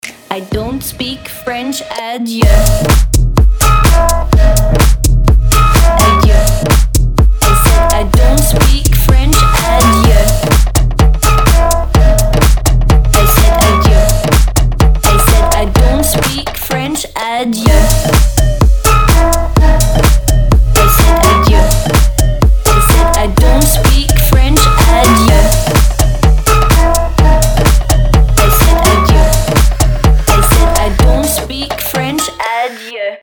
• Качество: 320, Stereo
громкие
EDM
future house
Bass House